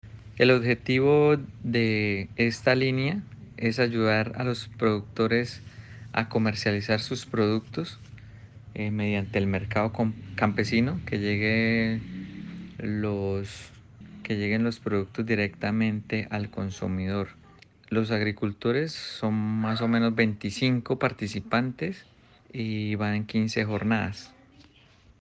Germán Hernández- Secretario de Agricultura.mp3